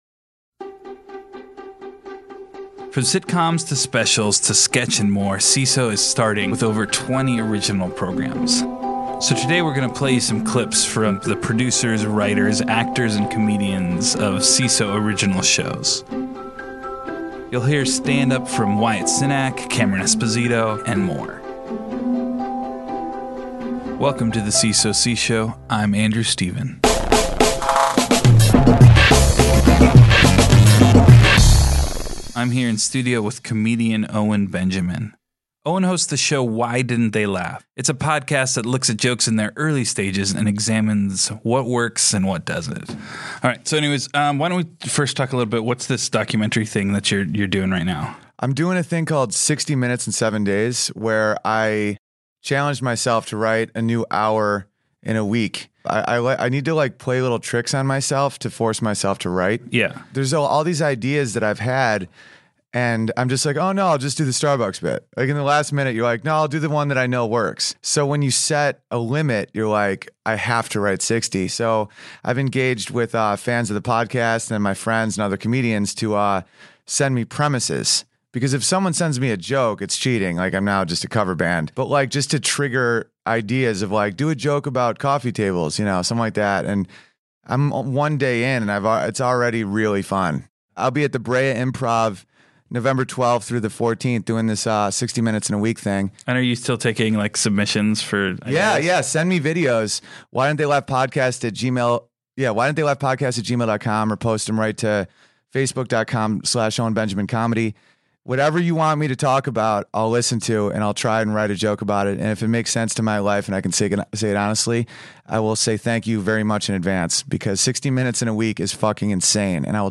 They say if you have to explain a joke, you've ruined it... but that’s exactly what we did. Joined by comedian Owen Benjamin, today we're spotlighting some of our favorite stand-up from Seeso contributors.